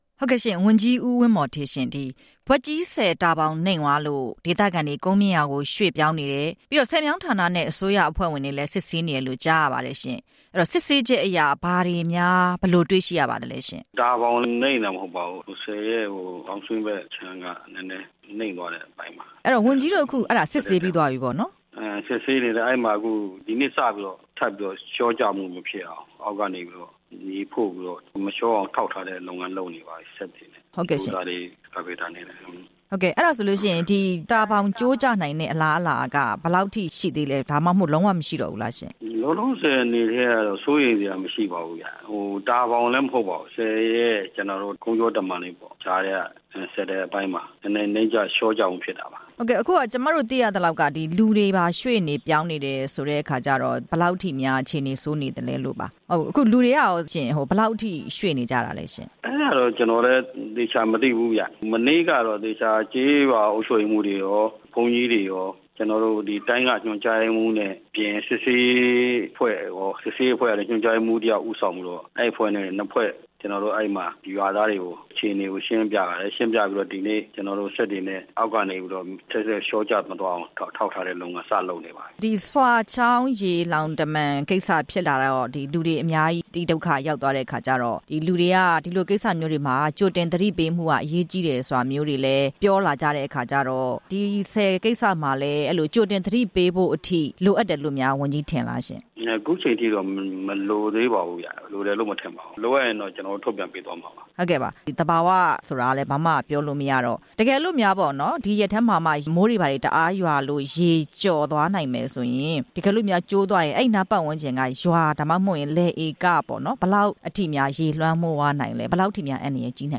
အောင်လံဘွက်ကြီးဆည် မြေသားဘောင်နိမ့်ကျမှုအကြောင်း မေးမြန်းချက်